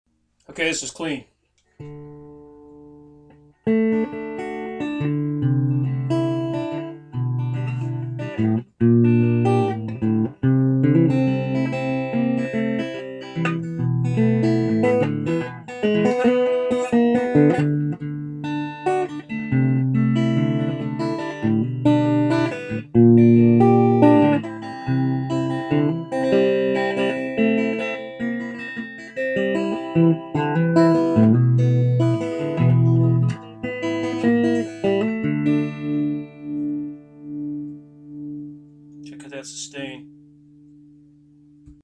It may come as a surprise to some, but she can be remarkably clean and sweet.
• Both of these cuts are recorded with the same junky headset mike as above, into a laptop pc.
• Guitar volume set at max, which really helps sustain and sounds best to me.
Cut 4: Another Clean Cut